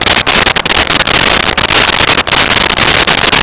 Sfx Amb Waterpipe Loop
sfx_amb_waterpipe_loop.wav